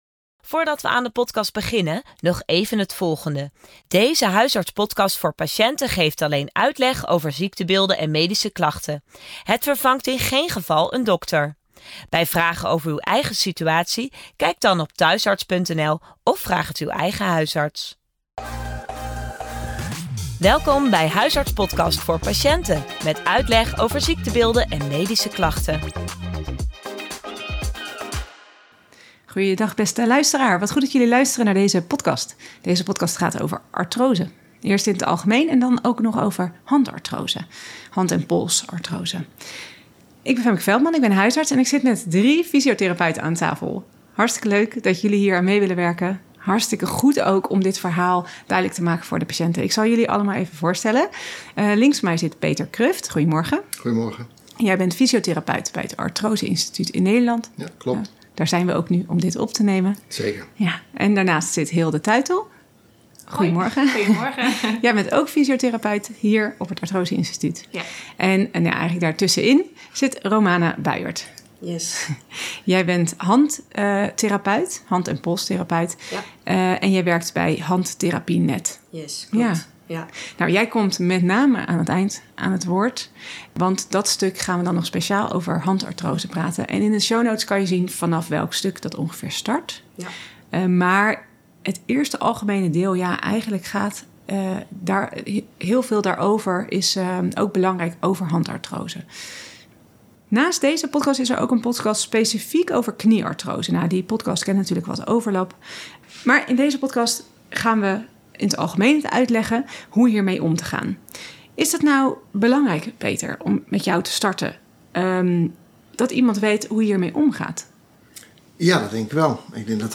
De sprekers hebben veel ervaring in de begeleiding van mensen met artrose.
Een interview